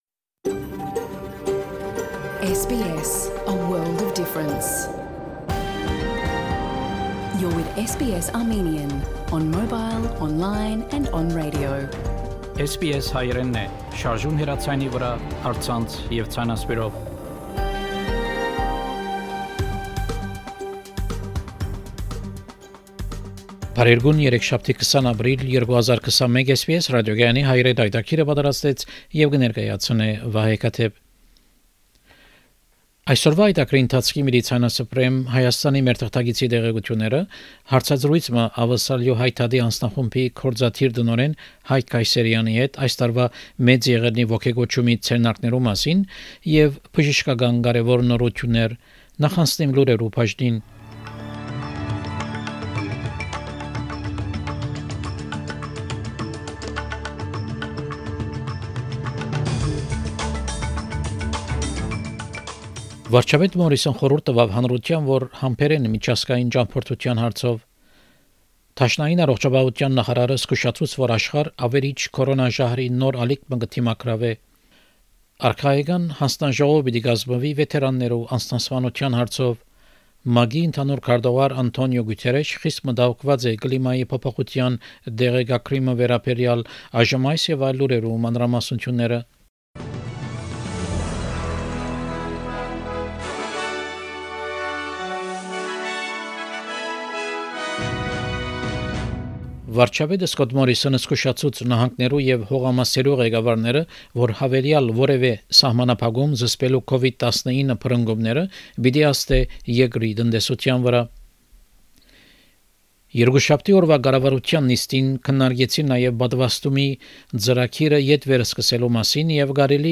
SBS Armenian news bulletin – 20 April 2021